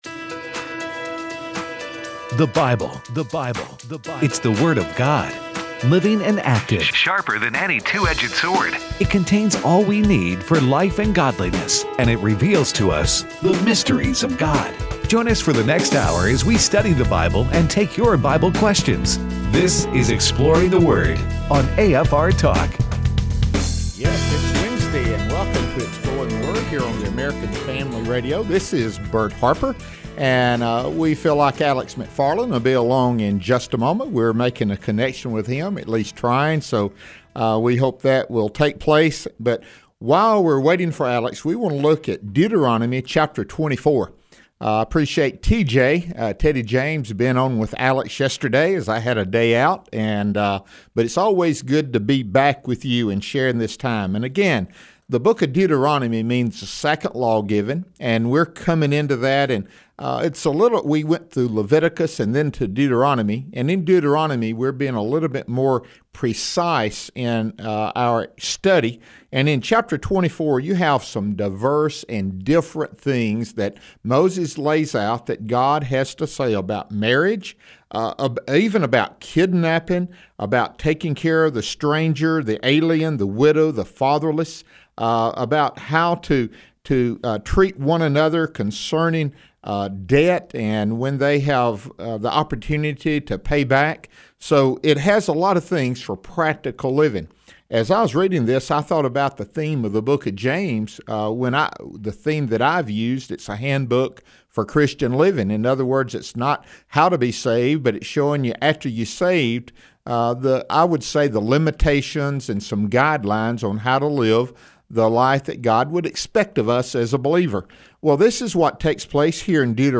discuss Deuteronomy 24 & 25 and take your phone calls.